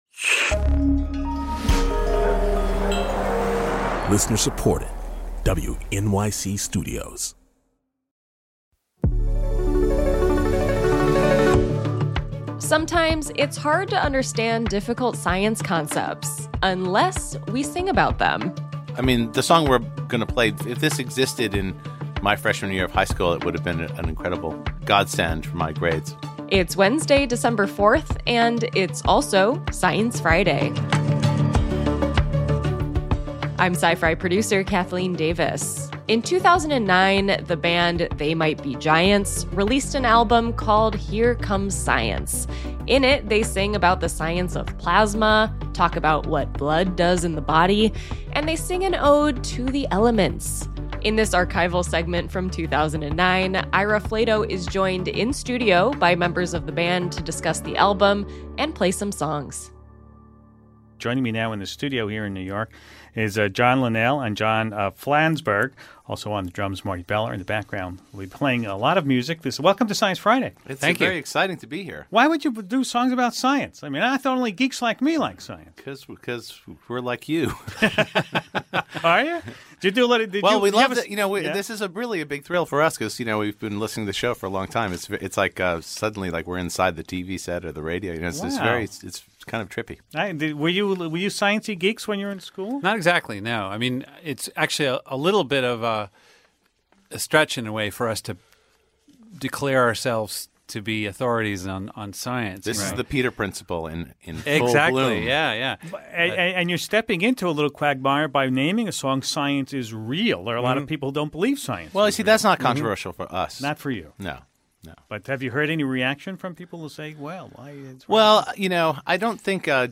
In a conversation from 2009, the band They Might Be Giants tackles the scientific process, plasma physics, the role of blood in the body and the importance of DNA, all in song.